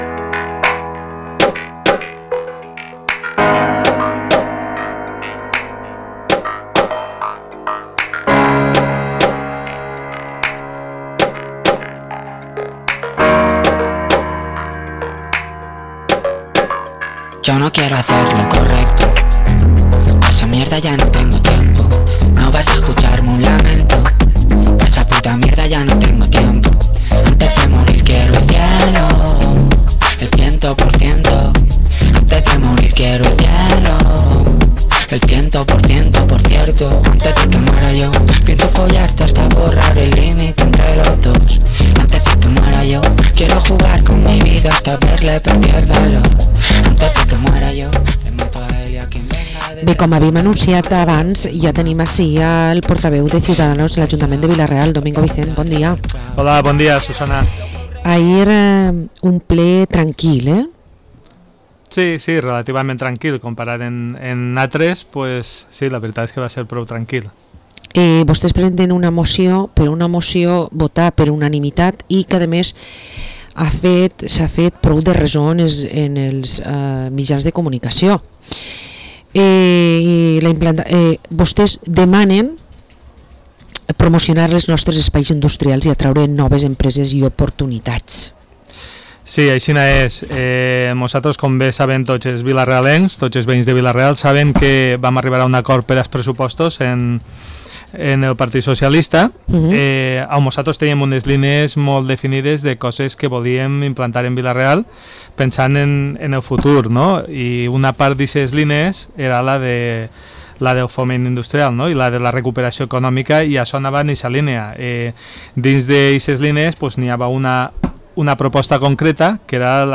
Entrevista al concejal de Ciudadanos de Vila-real, Domingo Vicent – Radio Vila-real 92.2 FM